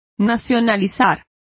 Complete with pronunciation of the translation of nationalising.